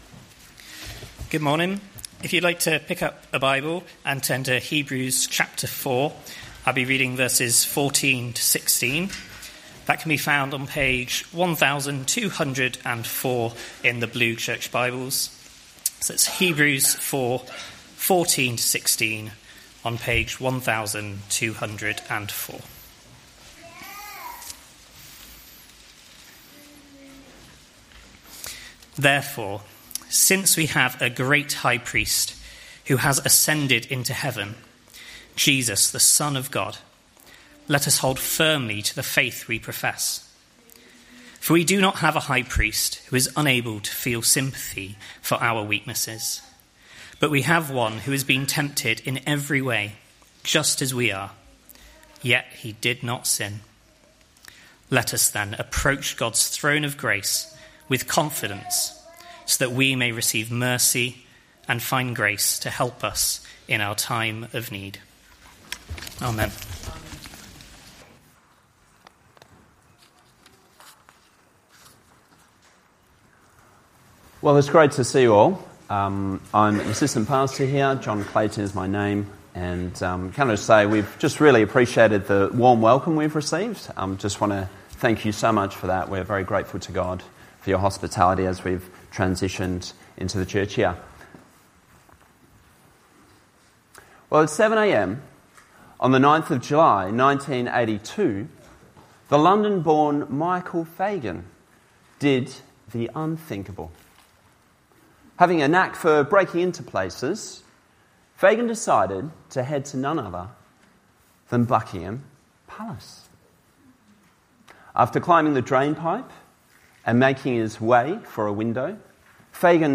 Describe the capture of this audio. Hebrews 4:14-16; 19 January 2025, Morning Service.